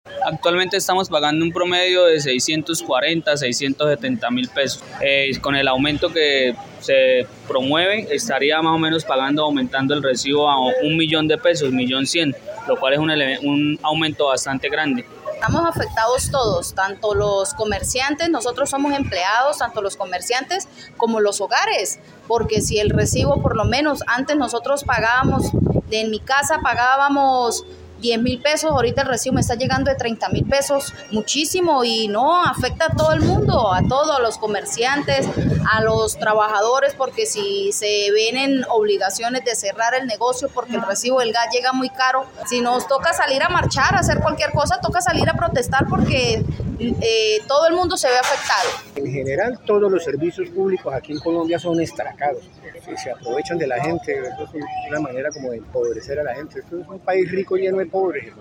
Voz comunidad, aumento tarifa de gas en Bucaramanga